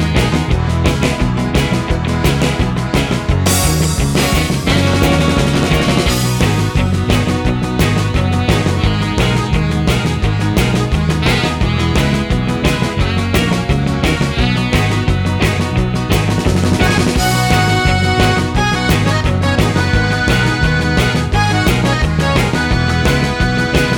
No Saxophone Solo Pop (1980s) 2:51 Buy £1.50